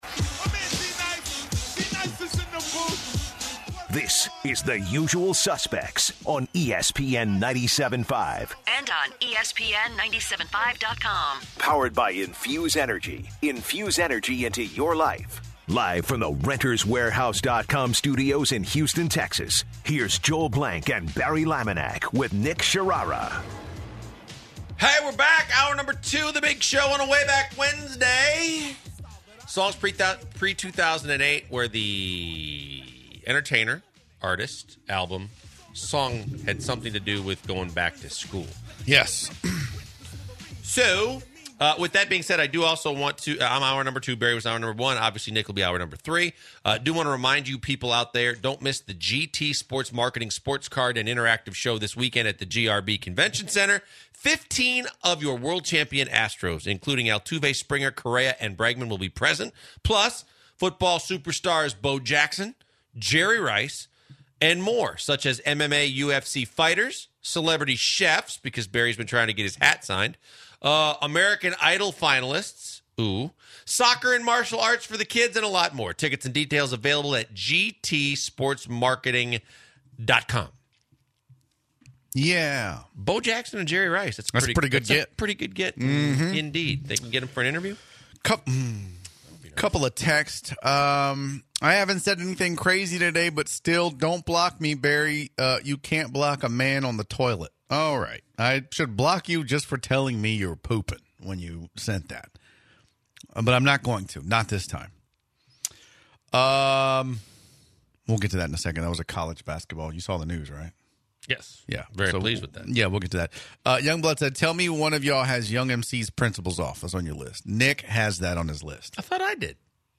The guys review the newly released NBA schedule and upcoming matchups. Next, they take calls from listeners about NBA talks. The guys also discuss college basketball players can now return to school if they go undrafted to the NBA.